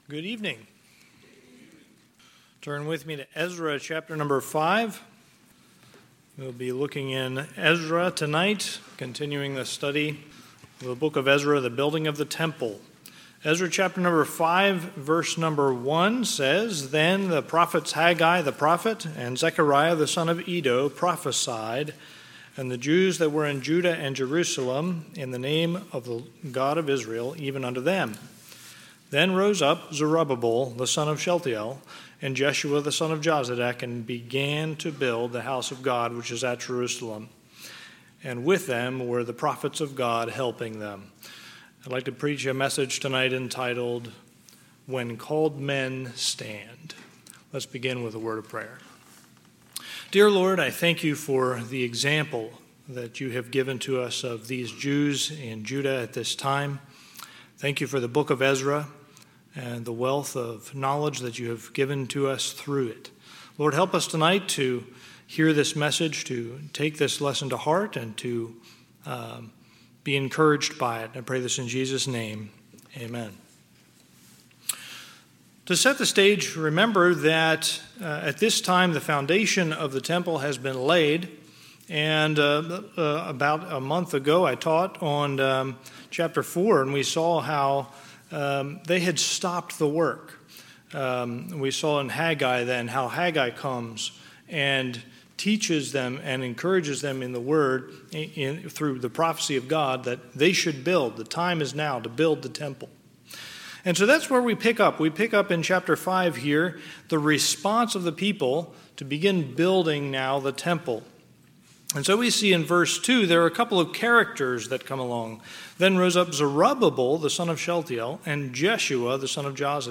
Sunday, March 2, 2025 – Sunday PM